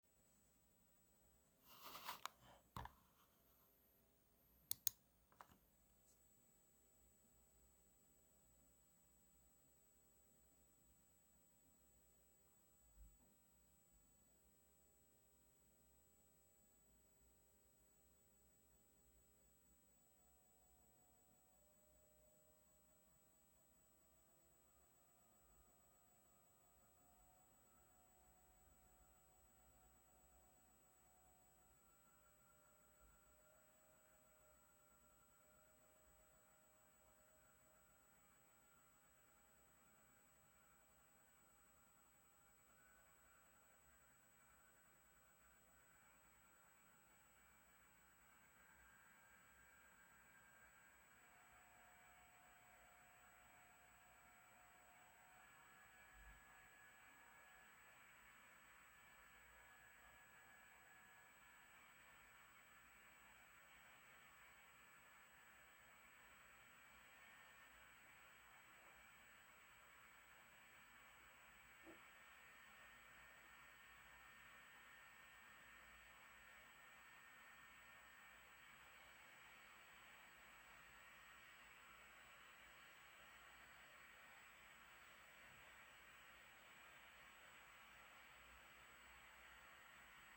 Die CPU hat nur 72, 73 Grad, dennoch dreht der Lüfter deutlich nach oben, schwankt zudem mit einem nervigen Geräusch.
Audio-Impressionen des Kühlsystems
Die Aufnahme erfolgte mit 40 Zentimetern Abstand zur Gehäusefront aus dem Leerlauf in einen CPU-Volllast-Benchmark.